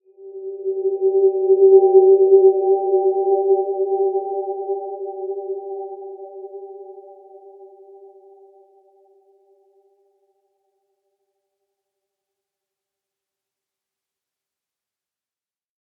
Dreamy-Fifths-G4-mf.wav